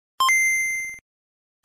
Nintendo Game Boy Startup Sound Button: Meme Soundboard Unblocked
Nintendo Game Boy Startup